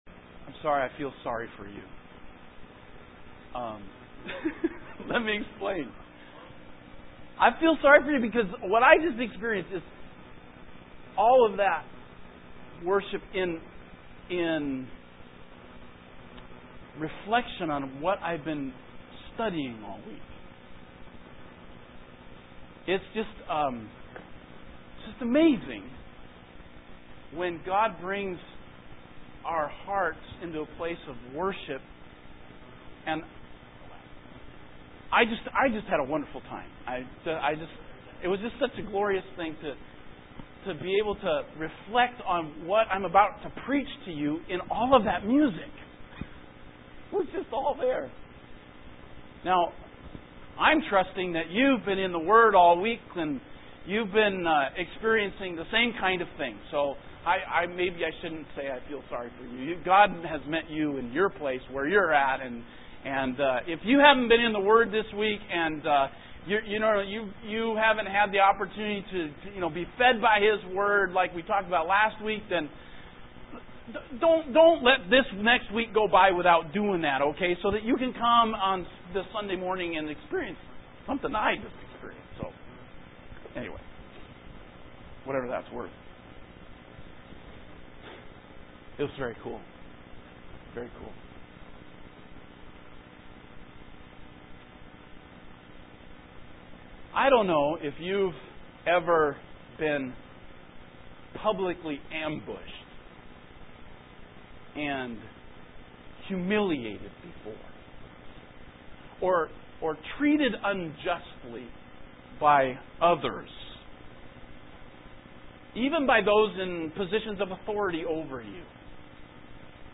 Evangelical Free Church in Washington State